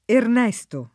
vai all'elenco alfabetico delle voci ingrandisci il carattere 100% rimpicciolisci il carattere stampa invia tramite posta elettronica codividi su Facebook Ernesto [ ern $S to ; port. irn $ štu ; sp. ern %S to ] pers. m.